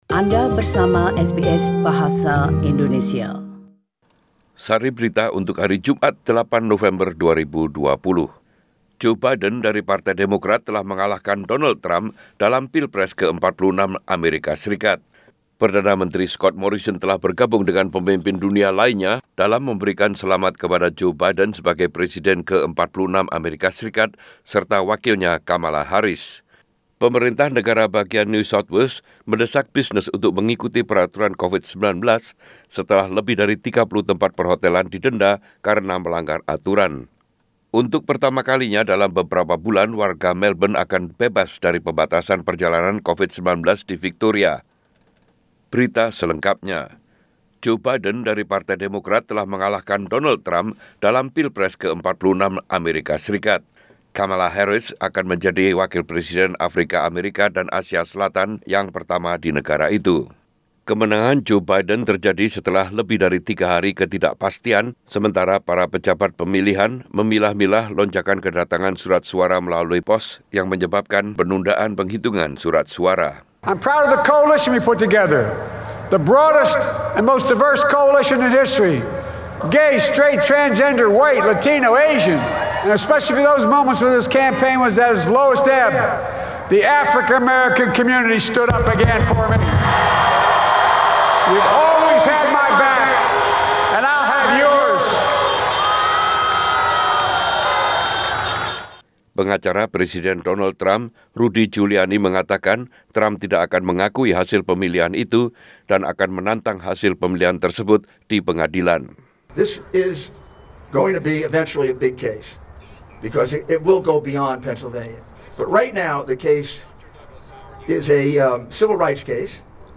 SBS Radio News in Bahasa Indonesia n- 8 November 2020